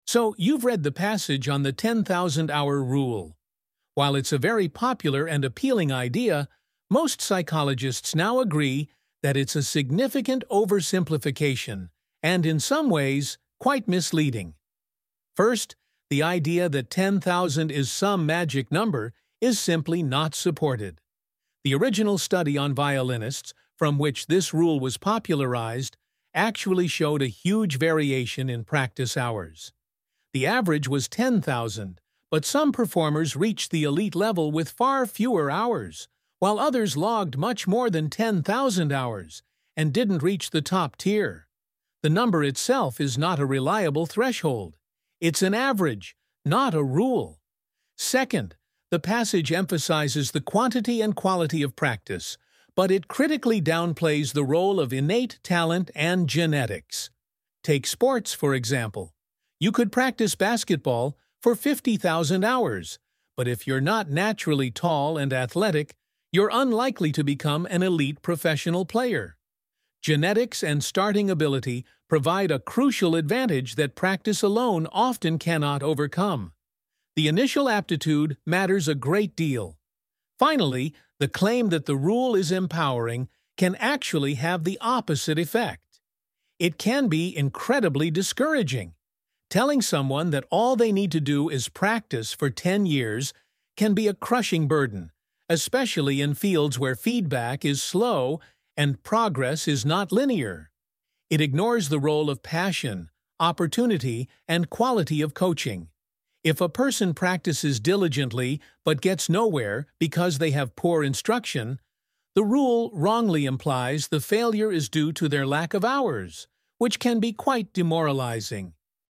2. Now, listen to a part of a lecture on the same topic.